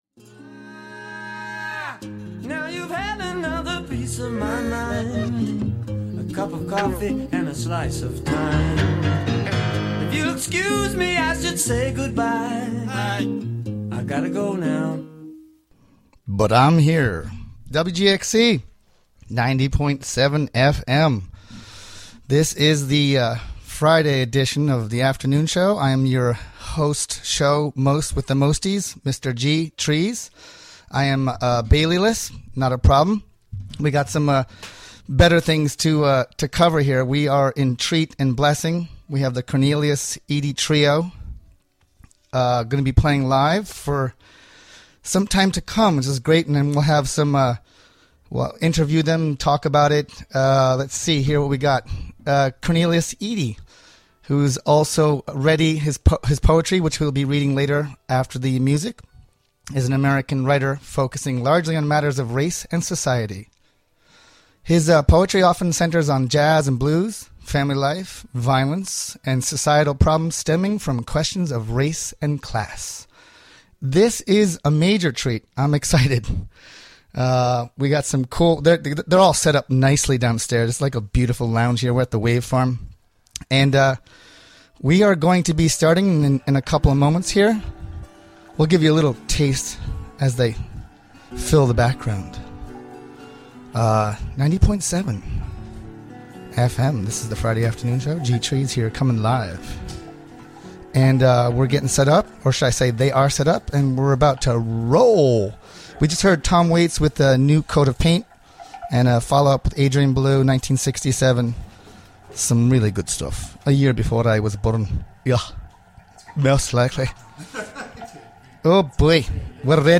guitarists
layered and graceful arrangements